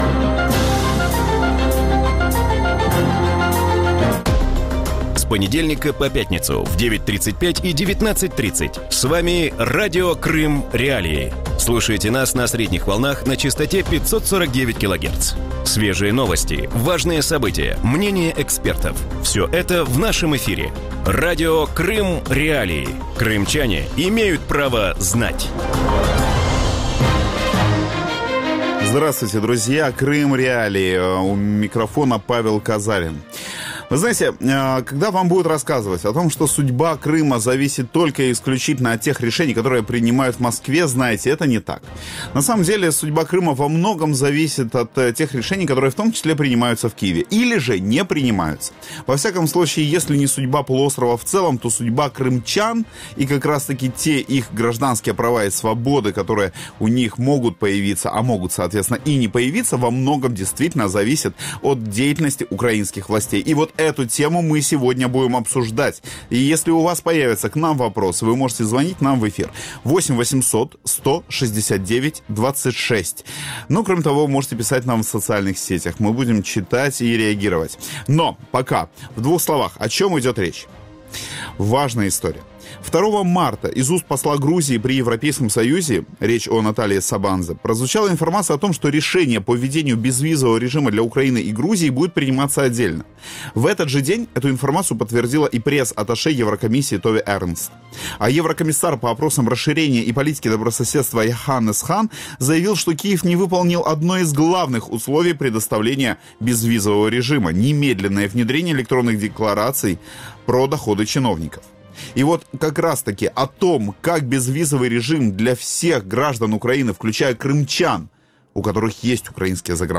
В вечернем эфире Радио Крым.Реалии обсуждают отсрочку вопроса об отмене безвизового режима для Украины с Европейским союзом.